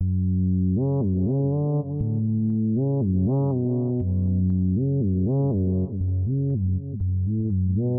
简单的弹跳节拍
描述：果味循环 爱迪生声音编辑器 蹦蹦跳跳的Hardhouse groovy